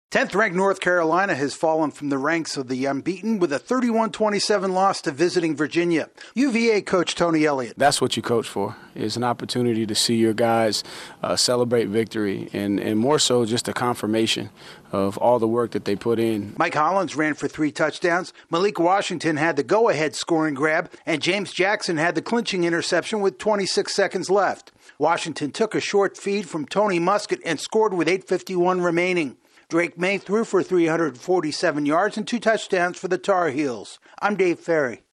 Virginia pulls off a stunner on Tobacco Road. AP correspondent